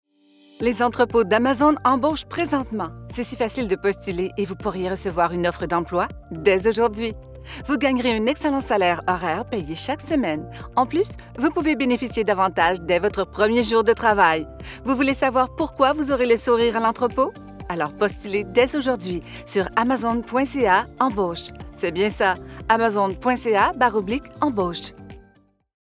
Vidéos d'entreprise
-Neumann TLM 103 Microphone
-Professional sound isolation recording booth